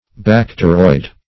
Bacteroid \Bac"te*roid\, Bacteroidal \Bac`te*roid"al\, a.